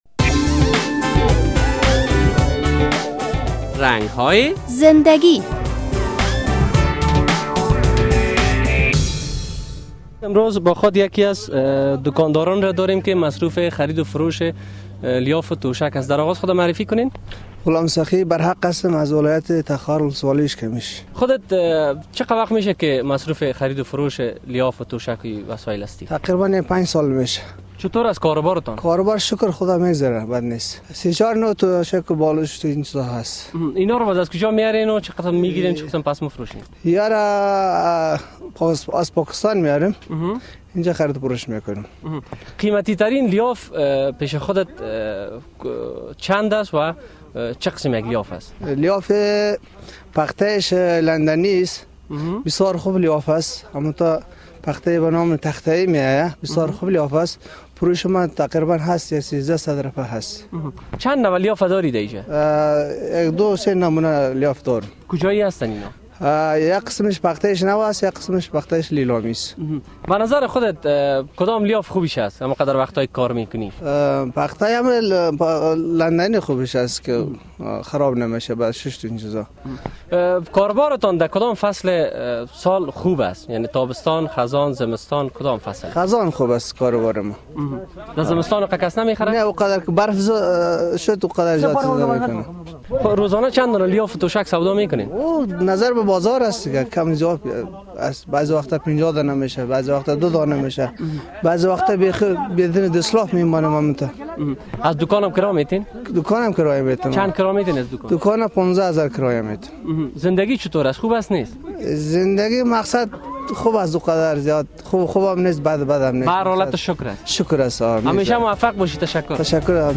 در این برنامهء رنگ های زنده گی با یک تن از دکاندارانی صحبت شده است که مصروف خرید و فروش لحاف و دوشک در کابل است.